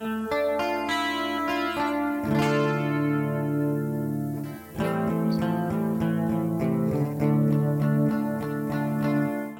描述：原声曲
Tag: 100 bpm Chill Out Loops Guitar Acoustic Loops 1.62 MB wav Key : D